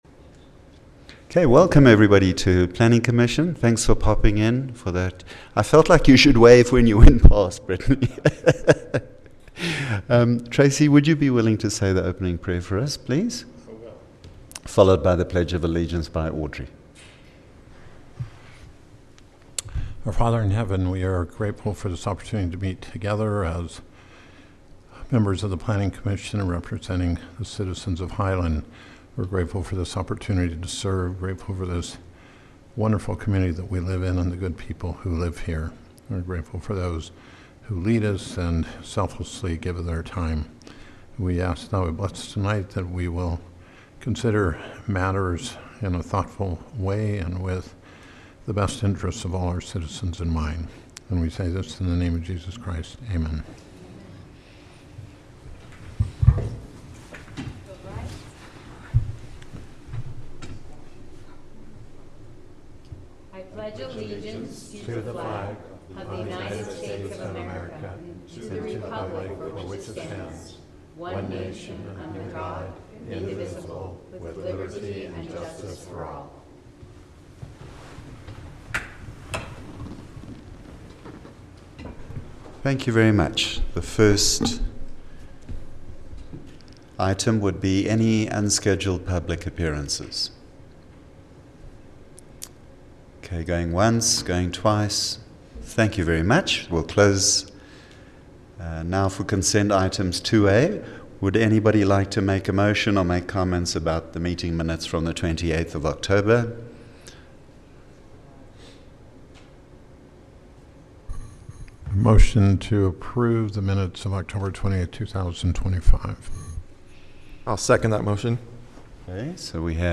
Highland Planning Commission Planning Commission Meeting
Highland City Council Chambers